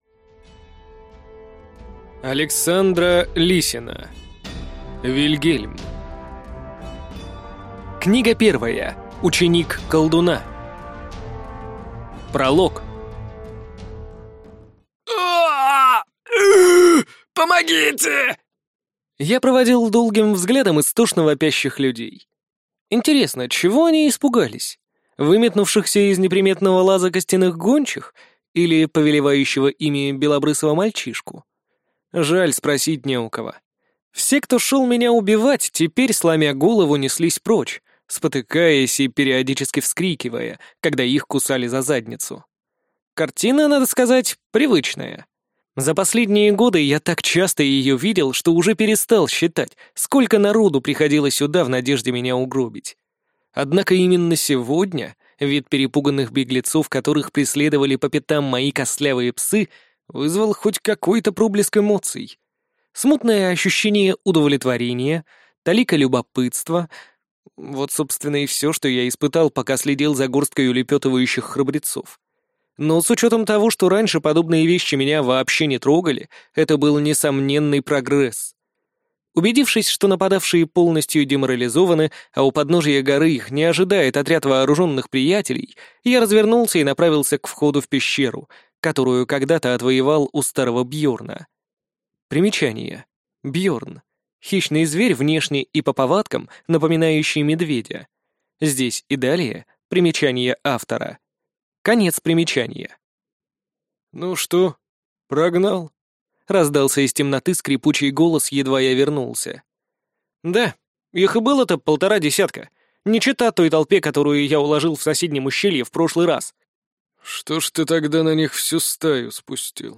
Аудиокнига Вильгельм. Ученик колдуна | Библиотека аудиокниг